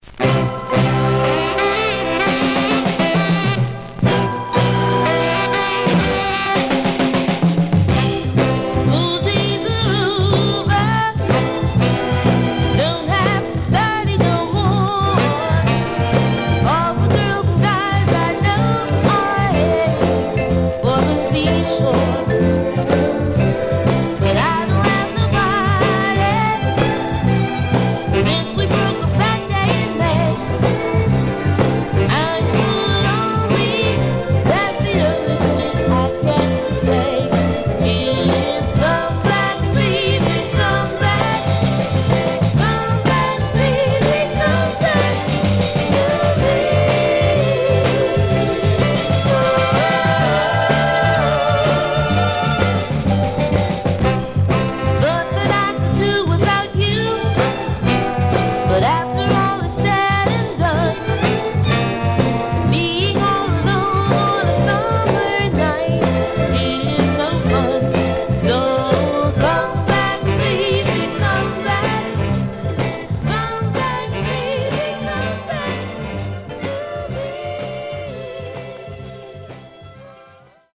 Surface noise/wear
Mono
Soul